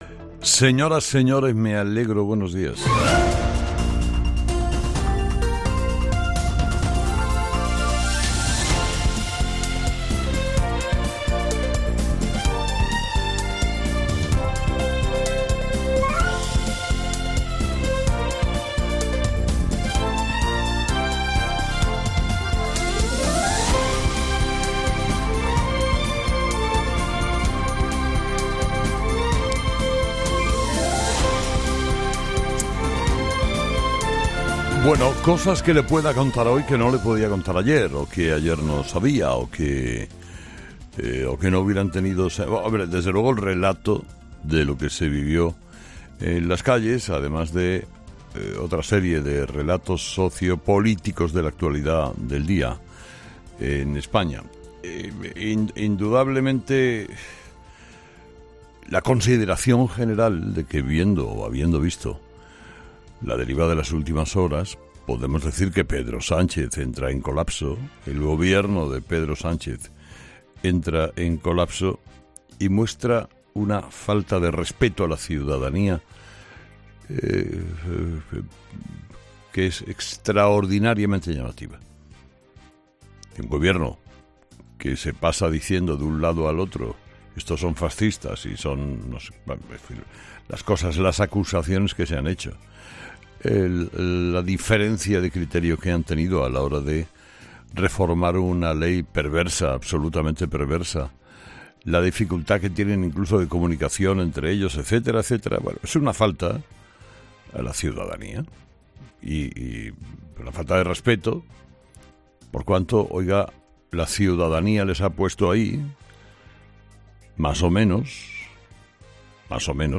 Carlos Herrera repasa los principales titulares que marcarán la actualidad de este jueves 9 de marzo en nuestro país